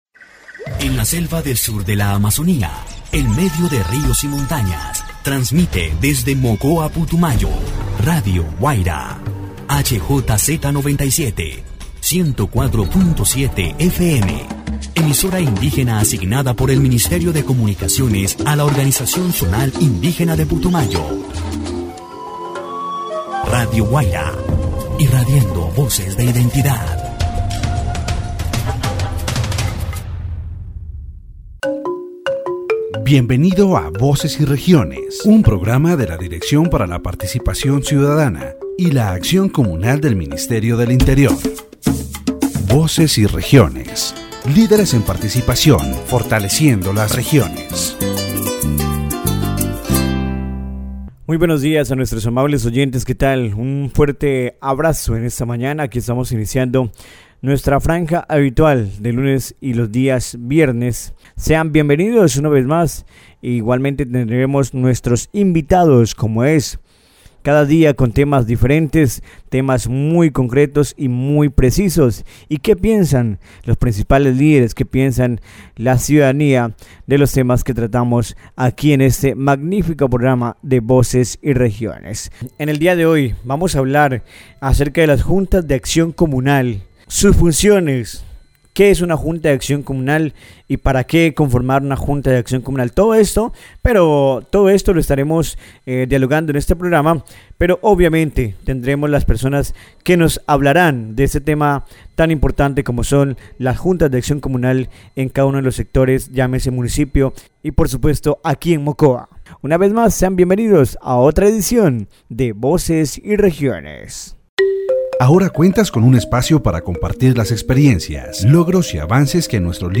The radio program "Voces y Regiones" on Radio Guaida 104.7 FM, directed by the Ministry of the Interior, focuses on the importance of Community Action Boards (JAC) in local communities.